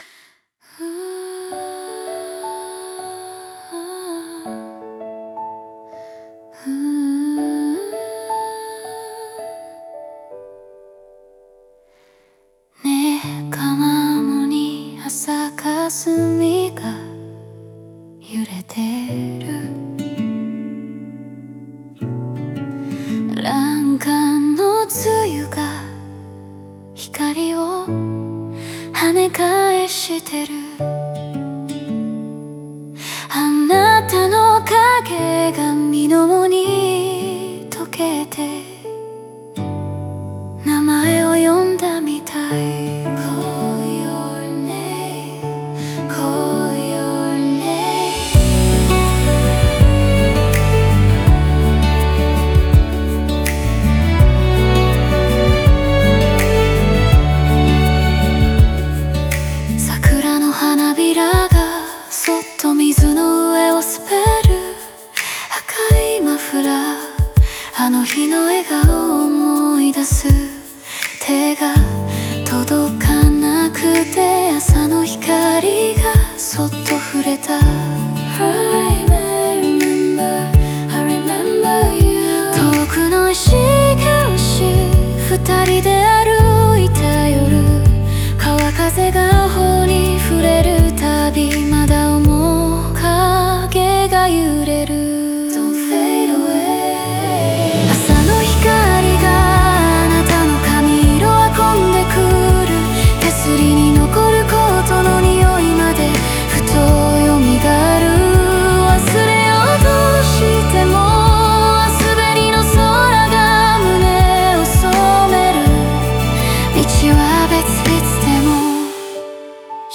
オリジナル曲♪
曲構成は抑制された叙情の中に少しずつ盛り上がりを持たせ、静かで優しく、映像的な余韻を残す。